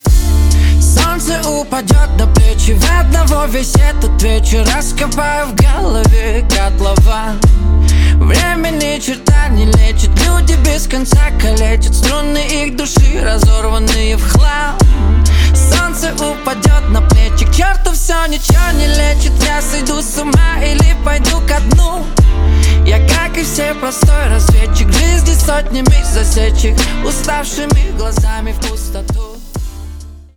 Поп Музыка
громкие # грустные